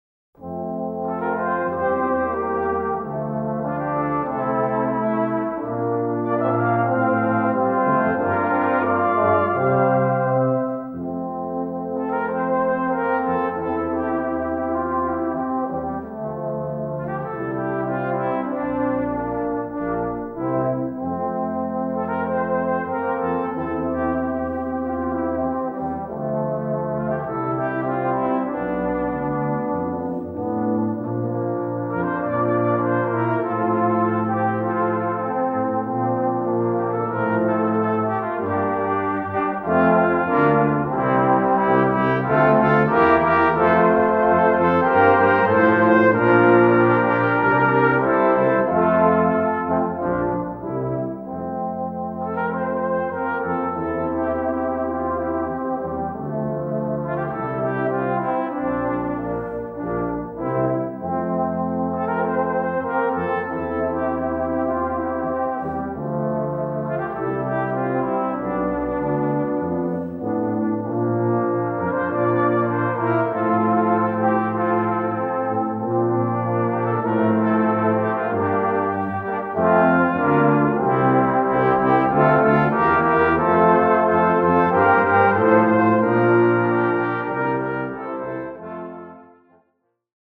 Gattung: Blechbläserensemble
Besetzung: Ensemblemusik für Blechbläser-Quintett
2 Trompeten/Cornet, Horn in F, Posaune in C, Tuba in C